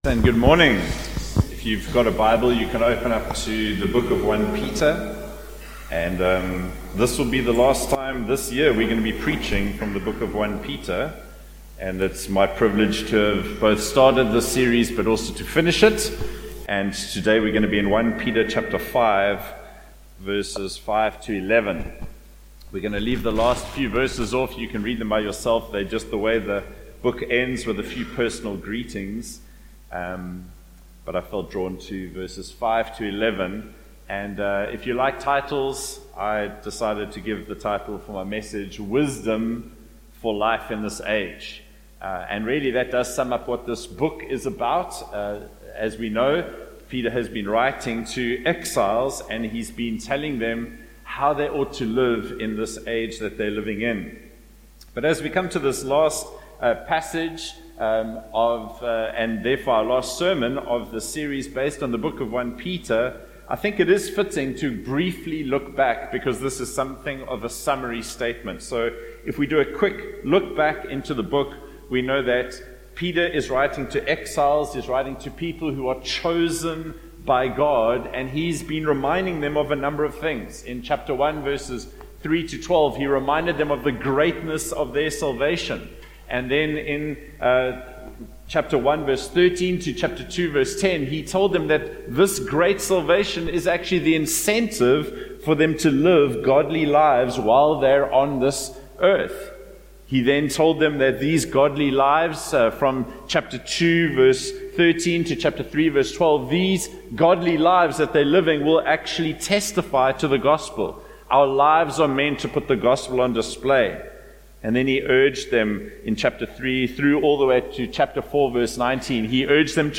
Join us for the first sermon from our new series on the book of 1 Peter: Hope-Filled Exiles - Hope & Guidance for the Age We Live In.